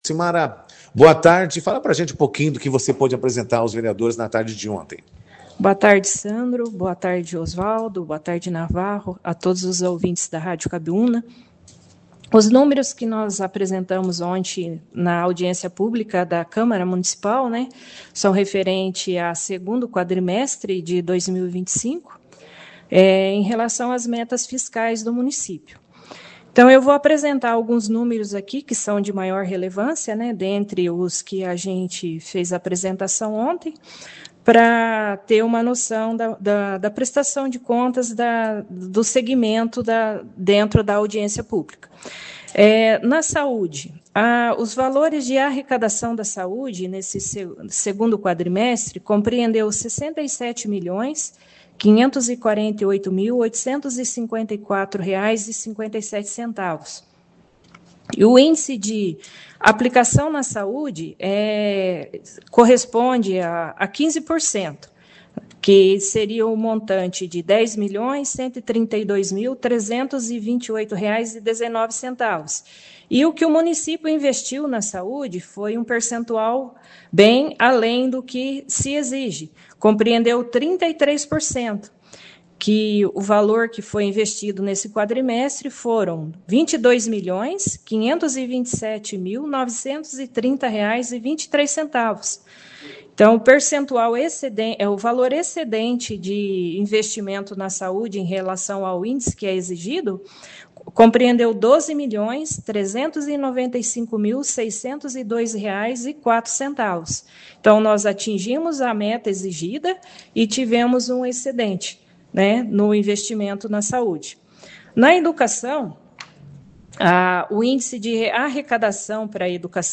As duas prestações de contas foram destaque na 2ª edição do Jornal Operação Cidade desta sexta-feira, dia 26, com a participação das representantes da Prefeitura e do SAAE.